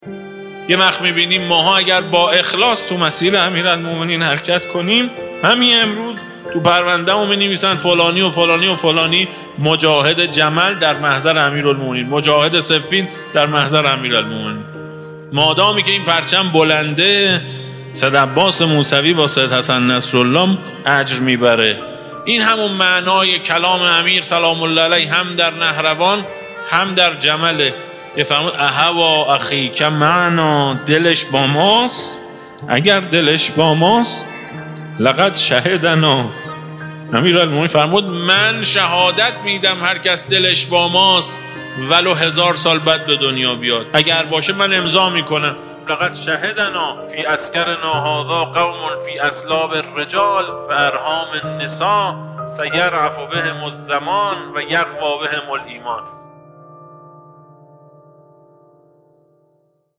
اگر اخلاص داشته باشیم انتشار: ۲۵ مهر, ۱۴۰۳ بدون دیدگاه بازدید: 12 دانلود فایل صوتی برگرفته از جلسه با عنوان “نصرت الهی” در حرم حضرت معصومه سلام الله علیها اشتراک گذاری دسته: کلیپ صوتی قبلی قبلی تدوین آثار شیعه در زمان امام عسکری علیه السلام بعدی اولین قدمِ جهاد بعدی